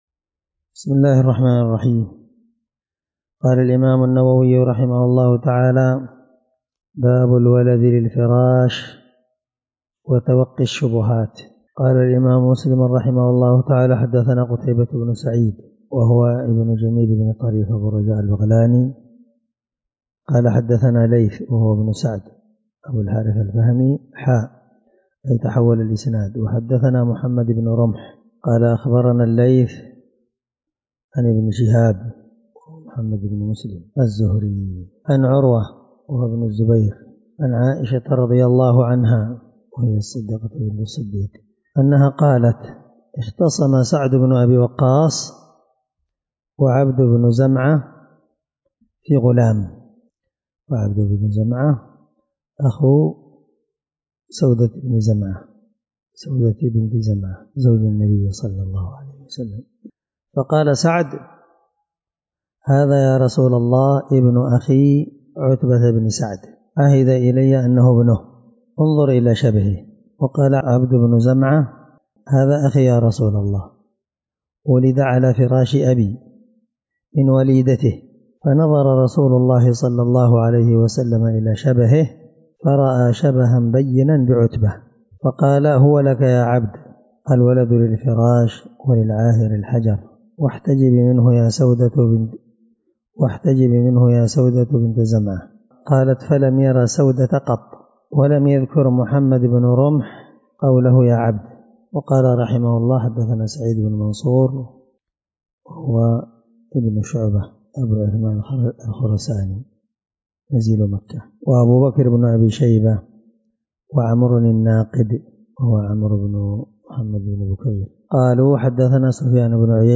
الدرس10من شرح كتاب الرضاع حديث رقم(1457- 1458) من صحيح مسلم